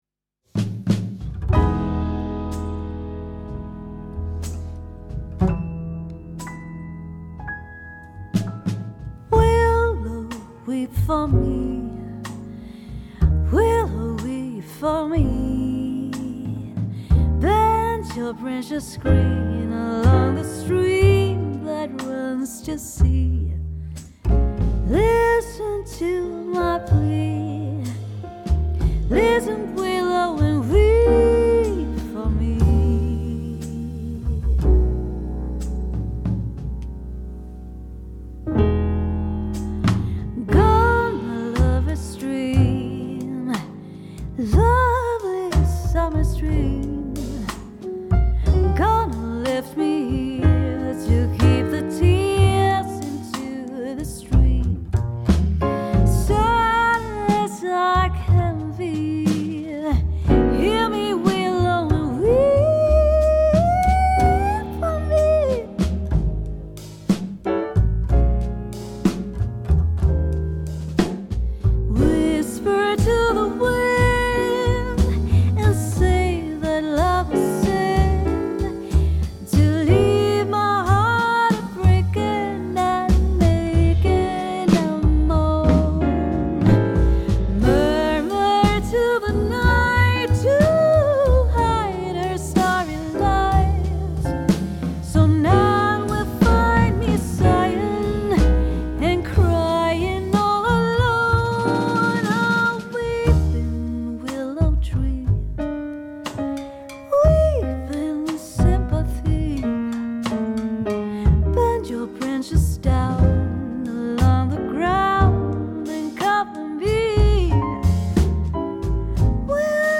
trombone
cornet